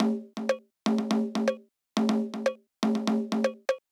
ORG Beat - Congas.wav